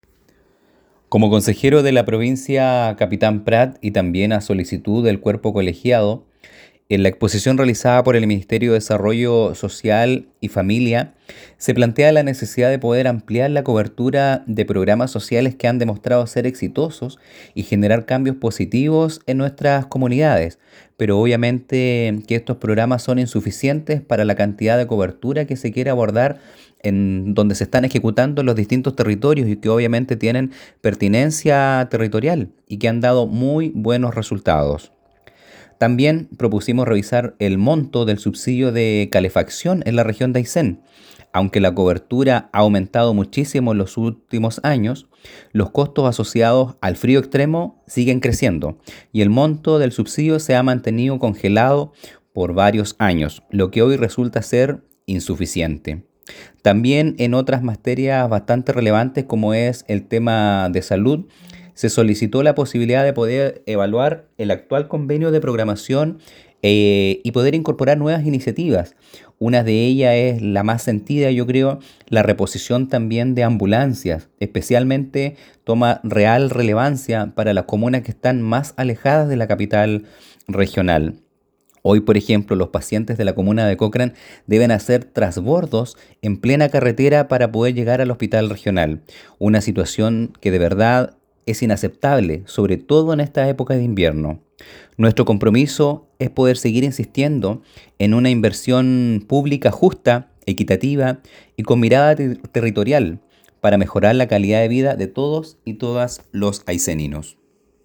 Consejero Rodrigo Rivera